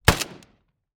Shot_v1.wav